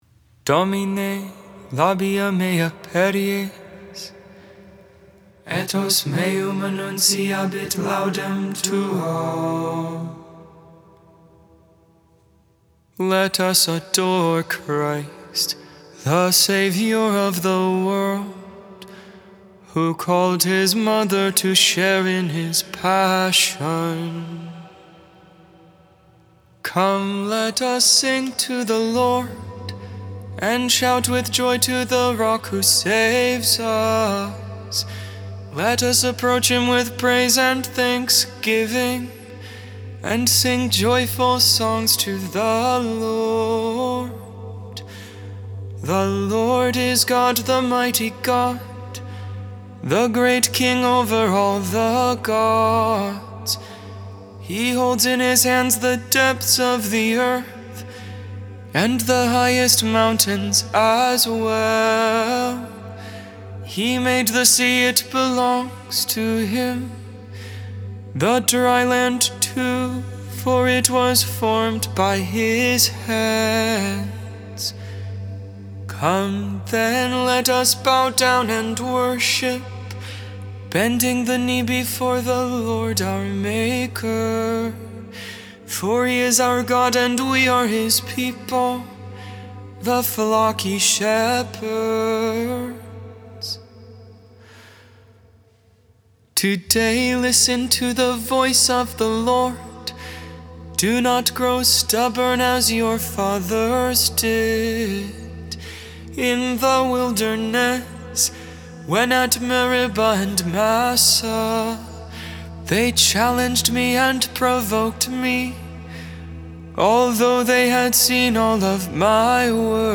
(Gregorian tone 1, straight through) Hymn: From "Stabat Mater," pg.436 in the Liber Hymnarius (mode 2) Psalm 63v2-9 (Gregorian mode 6, StH adaptations) Canti